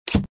GUI_quicktalker.ogg